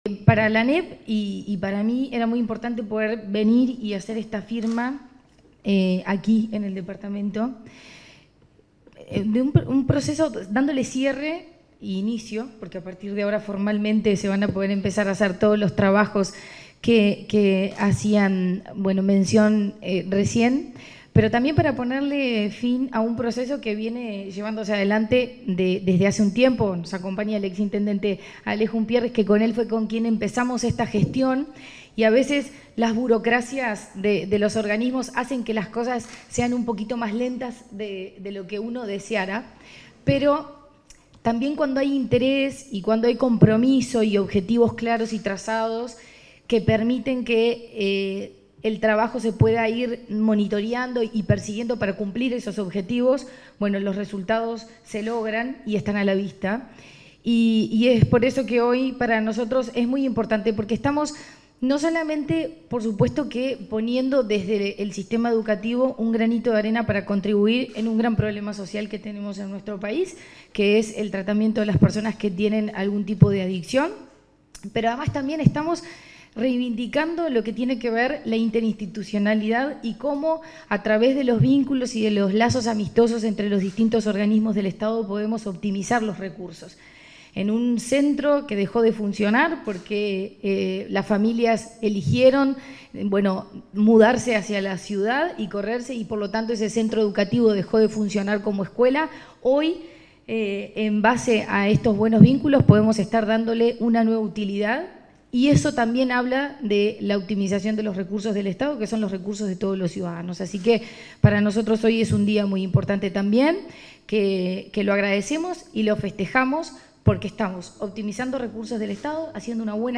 Palabras de la presidenta de ANEP, Virginia Cáceres
Palabras de la presidenta de ANEP, Virginia Cáceres 24/01/2025 Compartir Facebook X Copiar enlace WhatsApp LinkedIn En el marco de un acuerdo para ceder en comodato un inmueble a la Intendencia de Rocha, se expresó la presidenta de la Administración Nacional de Educación Pública (ANEP), Virginia Cáceres.